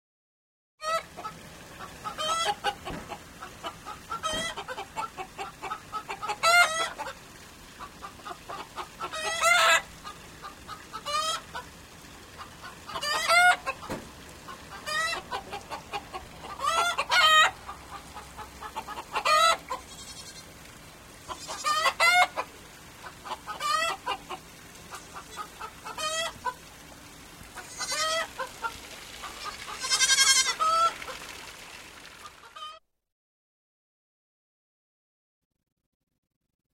uccelli_birds02.mp3